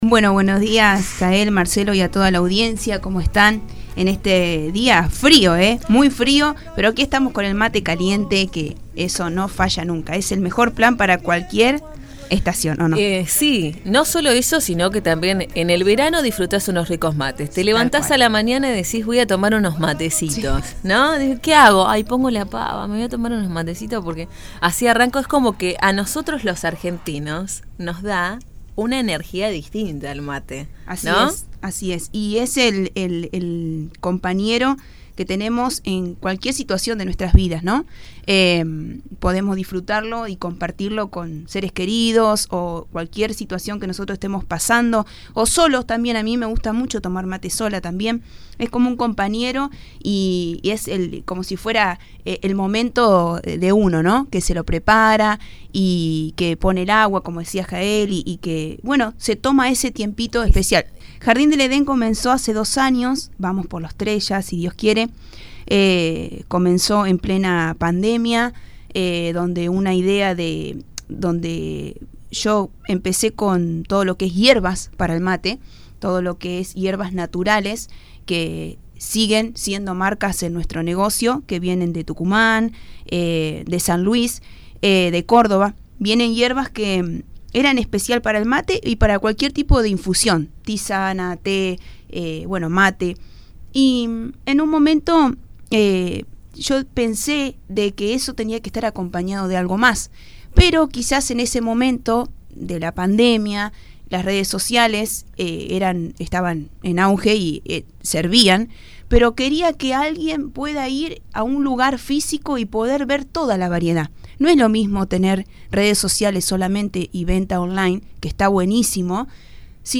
La especialista en yerba mate y té visitó los estudios de la 91.5 para conocer nuevos mitos y verdades de ambas infusiones.